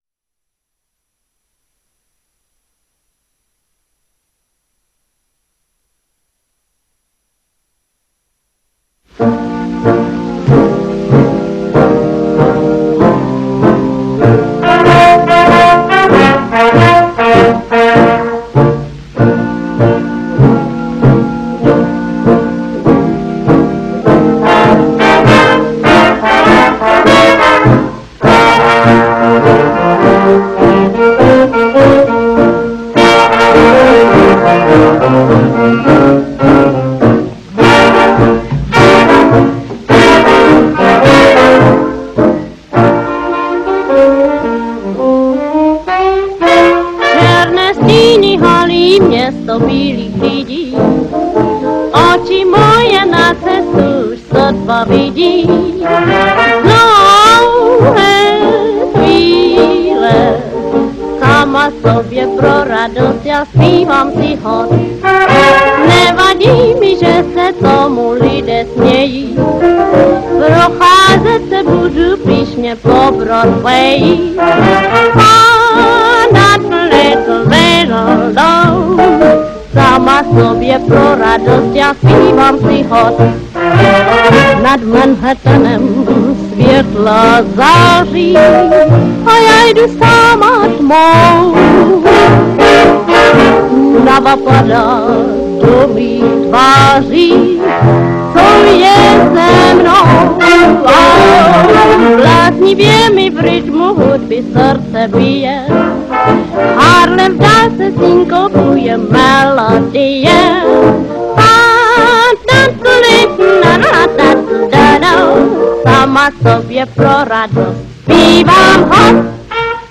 с оркестром